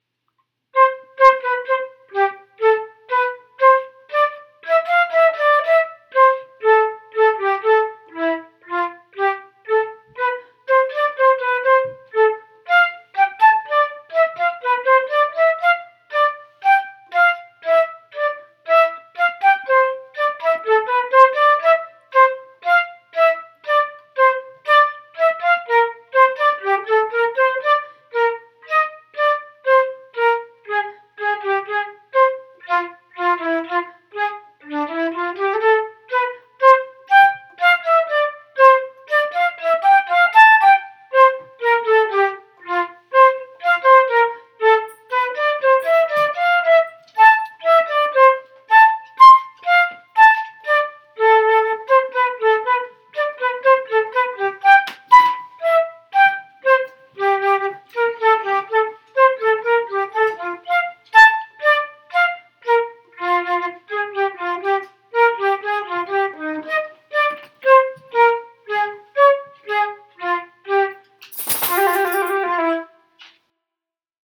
flute
Fugace at 60bpm